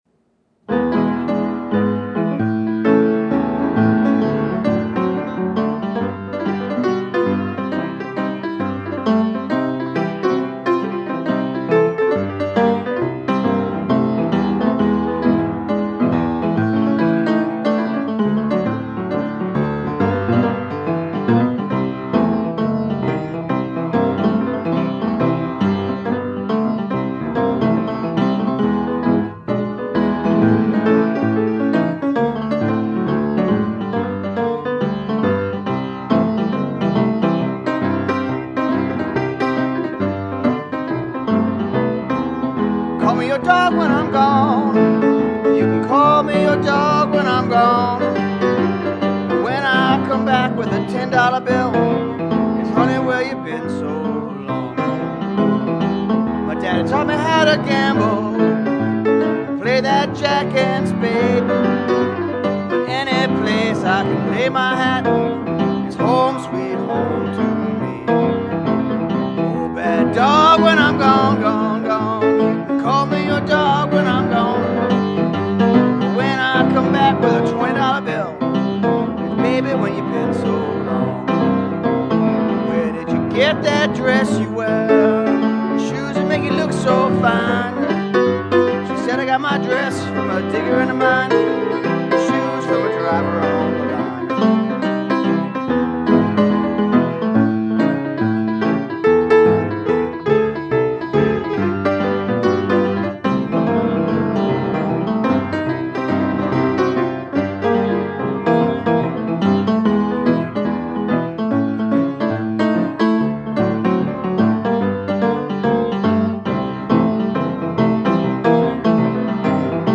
Piano
inside the 'Ark', in New Orleans,
a warehouse art and music club.